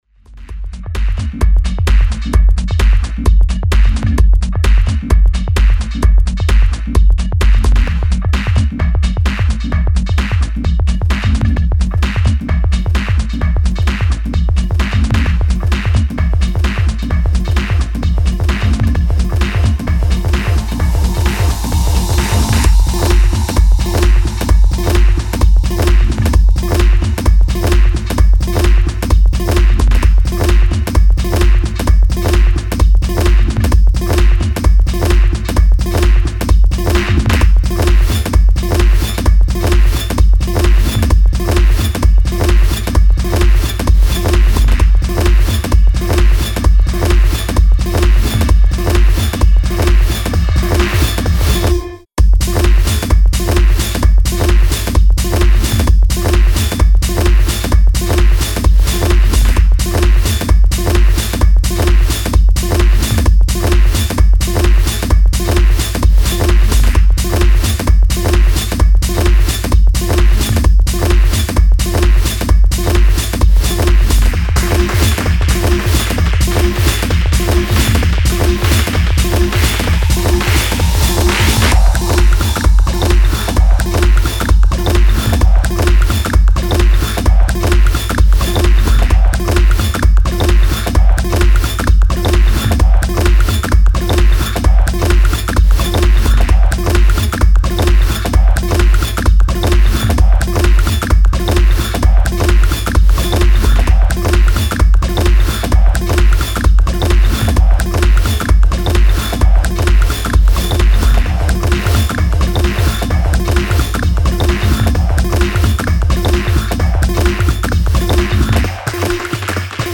a bassheavy Team-Up.
squirky, freaky, kickass, bassheavy and fast forward.
Style: Techno